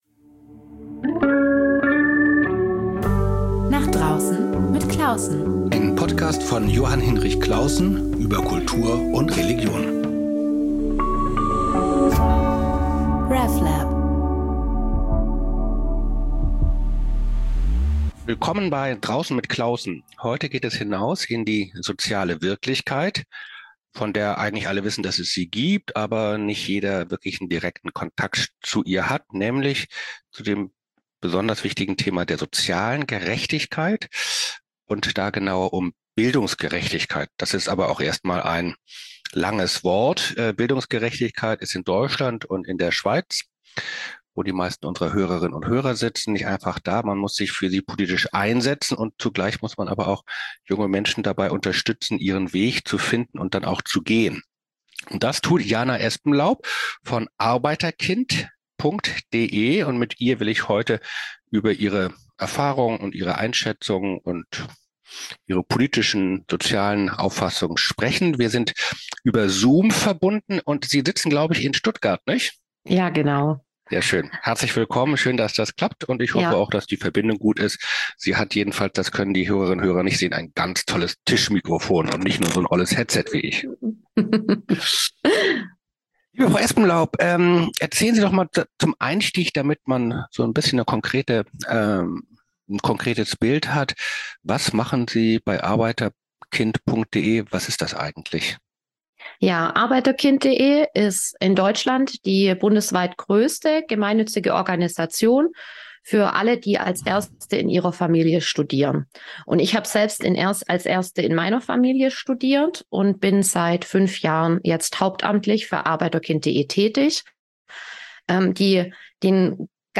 Nicht als journalistisches Frage-Antwort-Spiel, sondern als gemeinsames, ernsthaft-unterhaltsames Nachdenken.